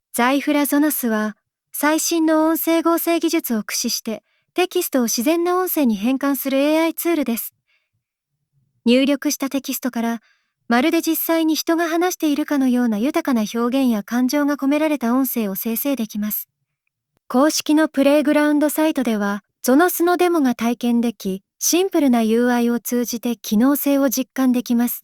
入力したテキストから、まるで実際に人が話しているかのような豊かな表現や感情が込められた音声を生成できます。
日本語入力で、流暢な日本語を話せます。